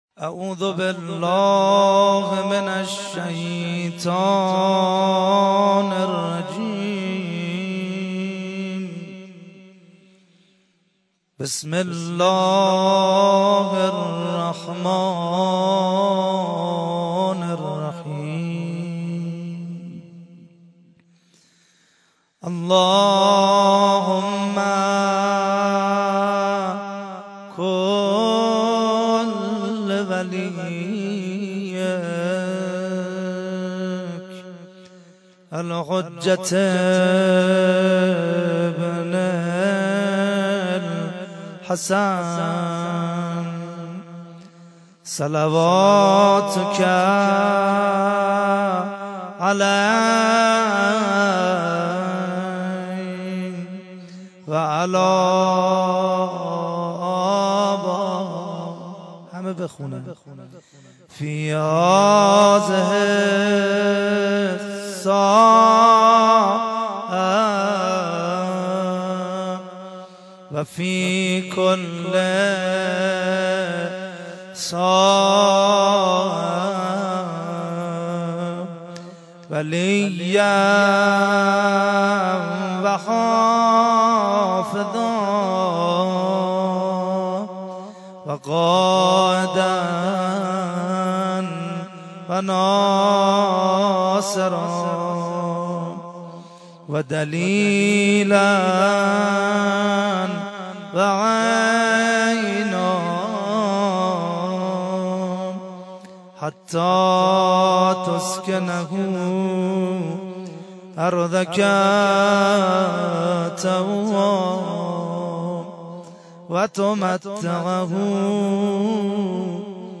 دعای کمیل در حسینیه شهدای بسیج